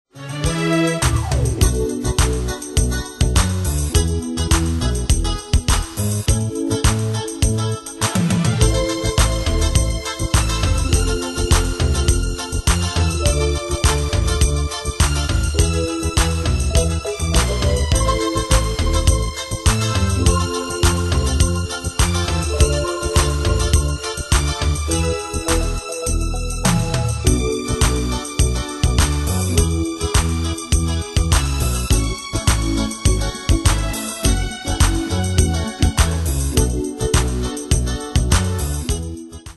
Style: Disco Année/Year: 1978 Tempo: 103 Durée/Time: 3.31
Danse/Dance: Disco Cat Id.
Pro Backing Tracks